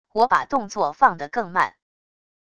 我把动作放得更慢wav音频生成系统WAV Audio Player